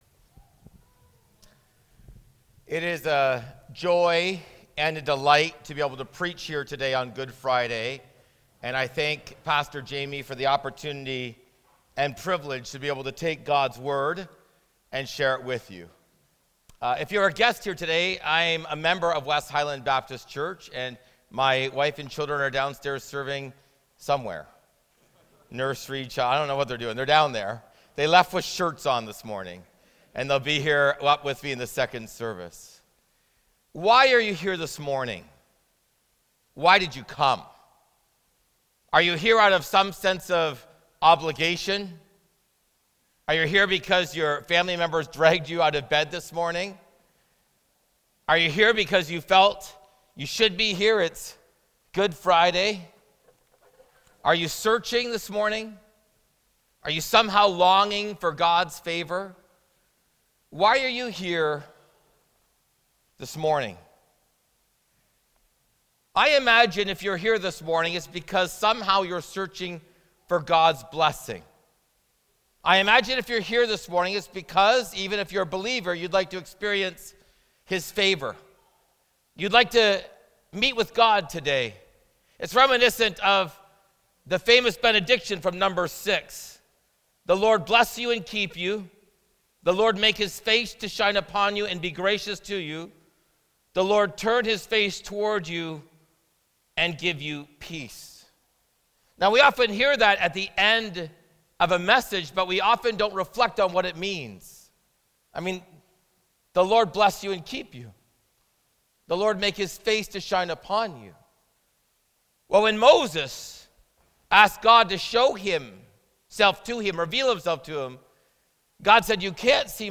Sermons Podcast - Was it Necessary for Jesus to Die?